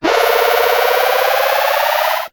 ihob/Assets/Extensions/RetroGamesSoundFX/Teleport/Teleport1.wav at master
Teleport1.wav